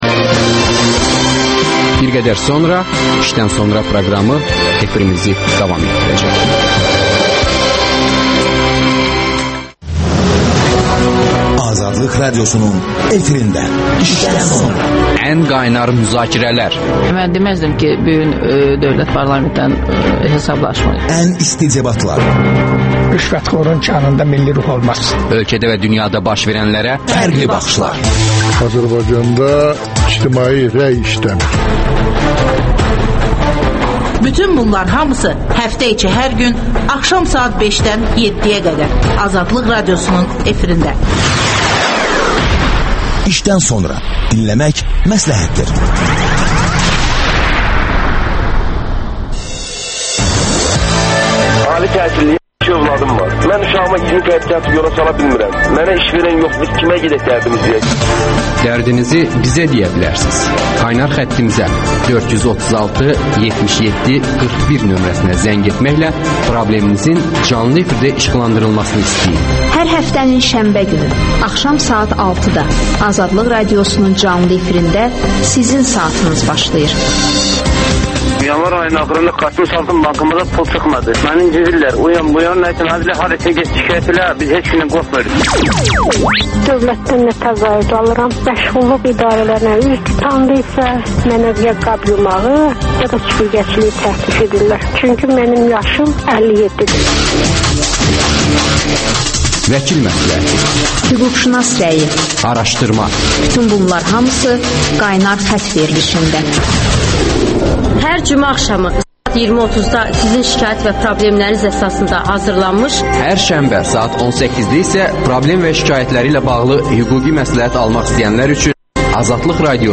İşdən sonra - Politoloq Vəfa Quluzadə ilə söhbət...